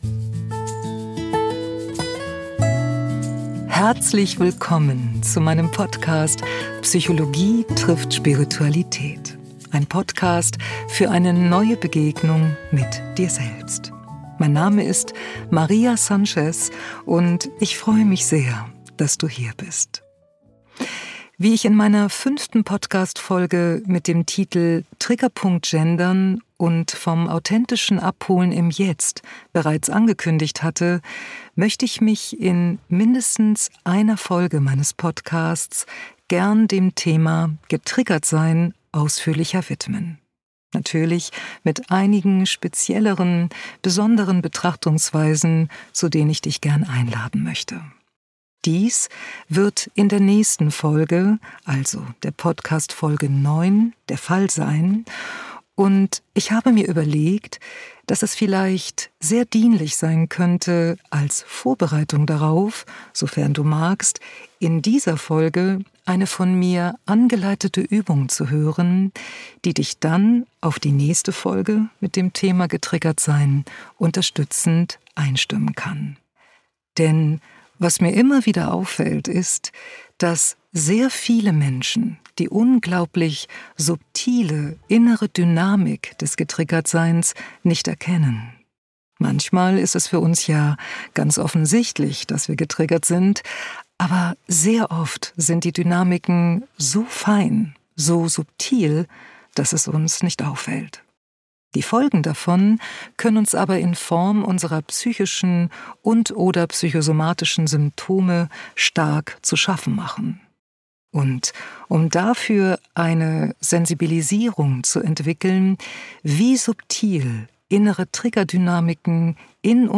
angeleitete Übung